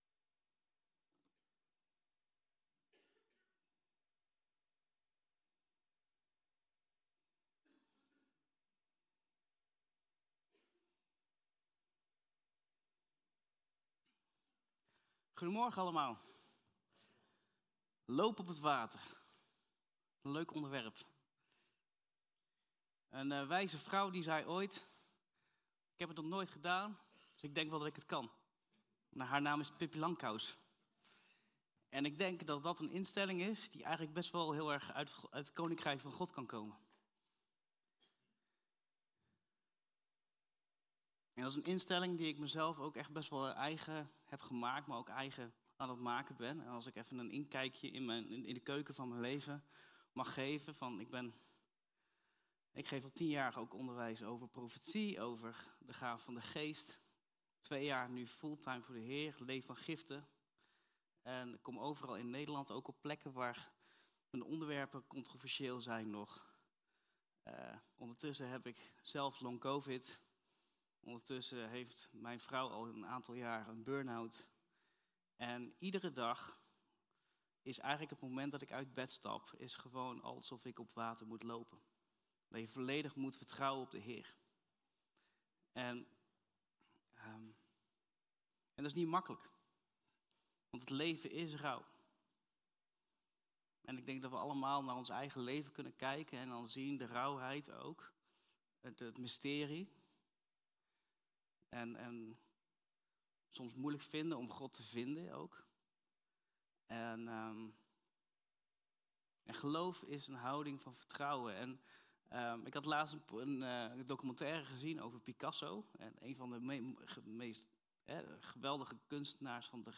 Toespraak 25 mei: Lopen op het water - De Bron Eindhoven